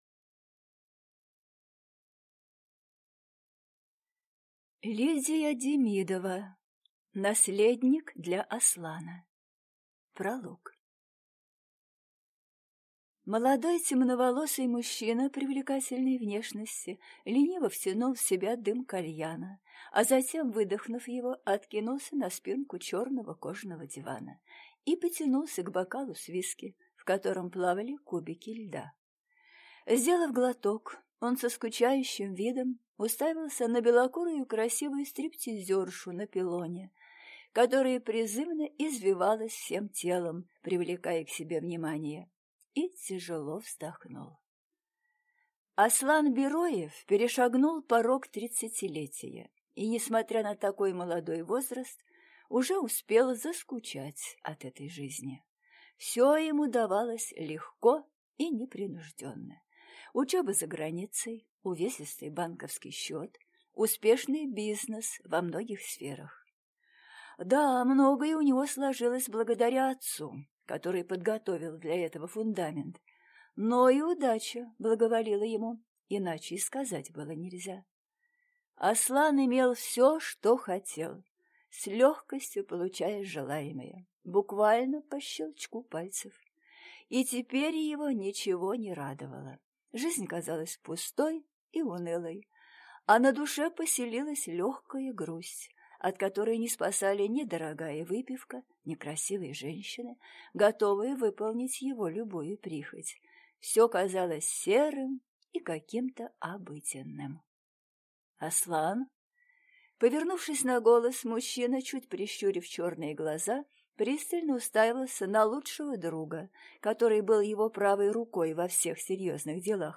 Аудиокнига Наследник для Аслана | Библиотека аудиокниг